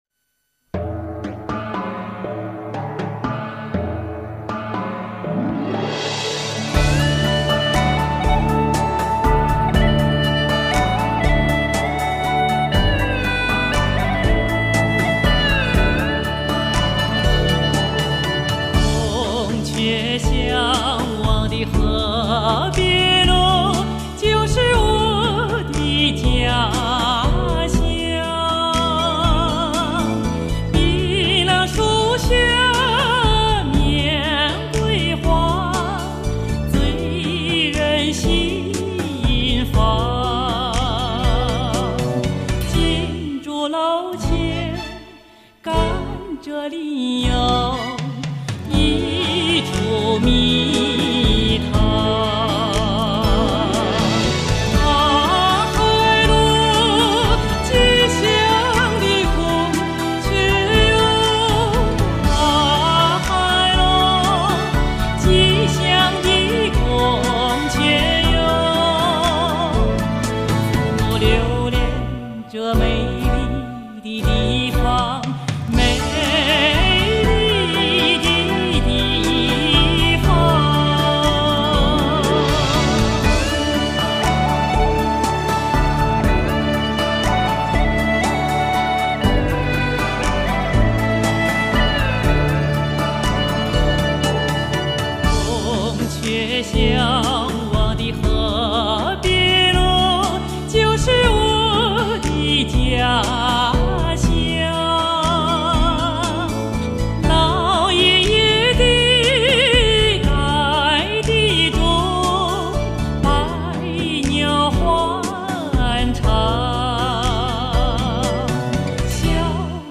深情并貌 雄浑秀美
录音棚：北京飞凡耳录音棚